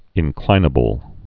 (ĭn-klīnə-bəl)